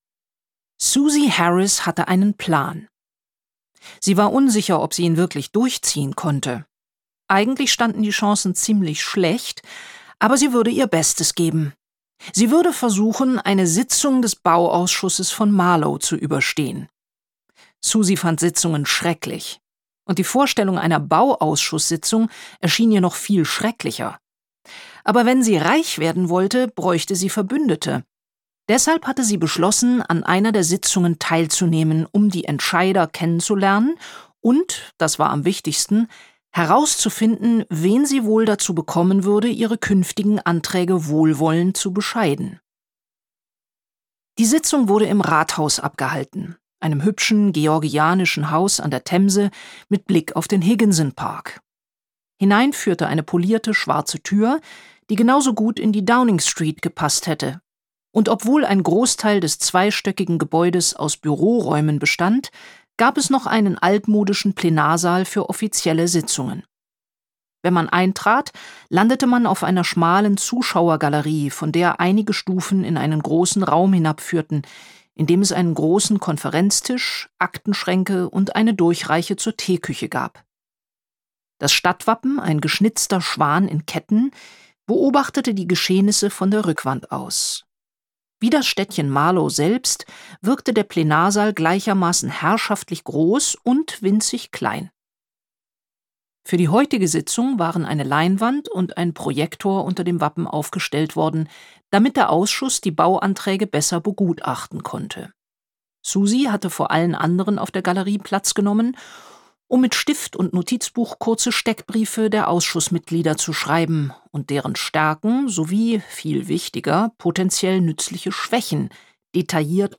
Interpret: Christine Prayon
Bei ihrer Intonierung der verschiedenen Personen hat man sofort Bilder im Kopf, wie sie aussehen.